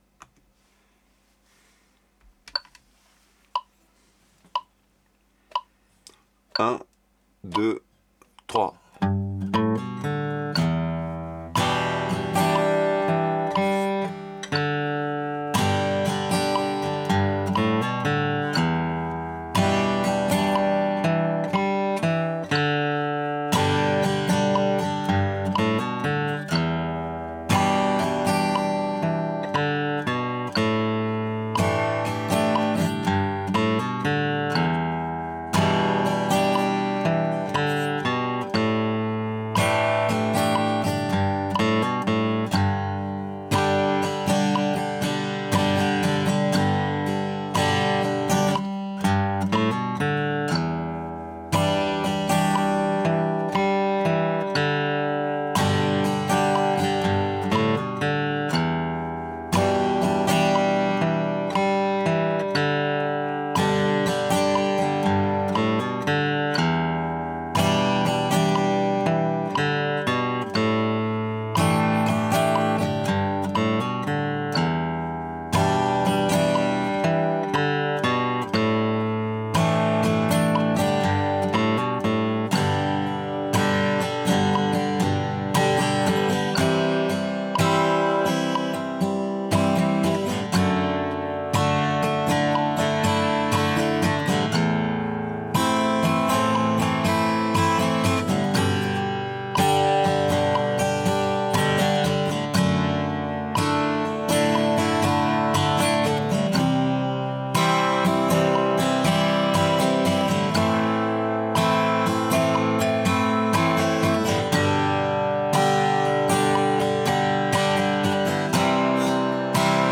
à la guitare
une intro picking et un rythme D-DU-UDU à 60 BPM.